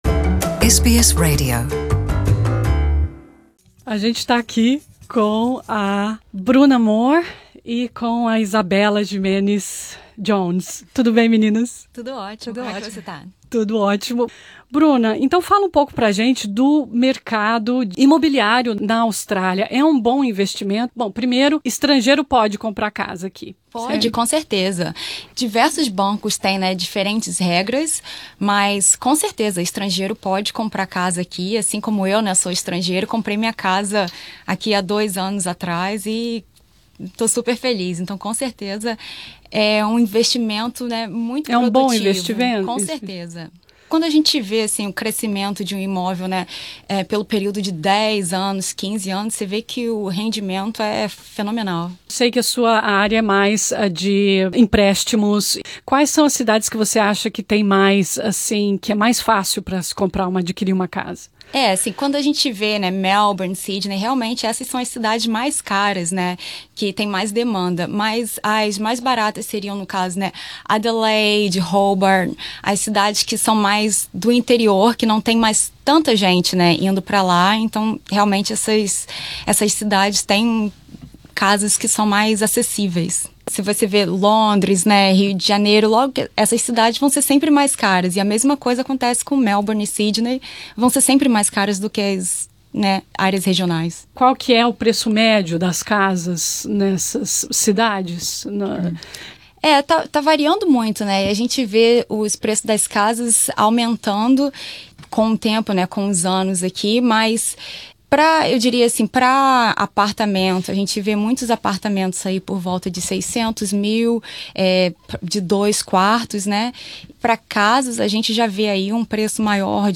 Aqui os principais trechos da entrevista.